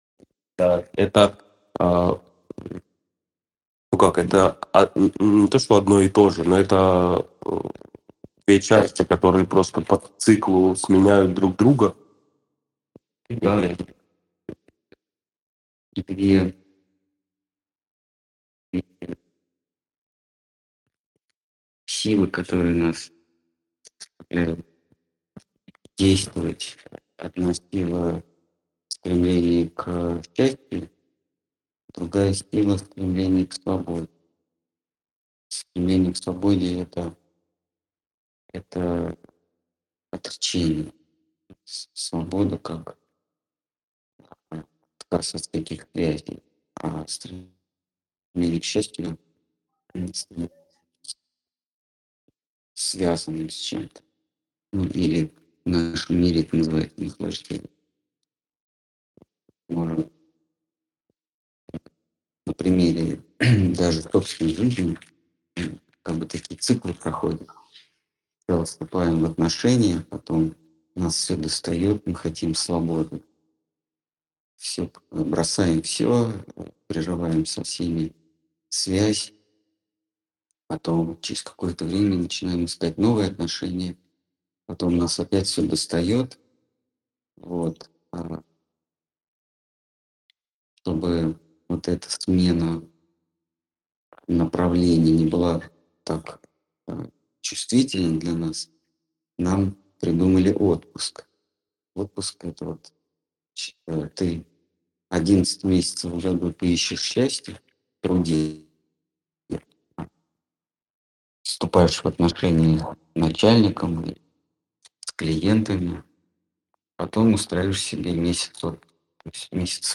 Ответы на вопросы из трансляции в телеграм канале «Колесница Джаганнатха».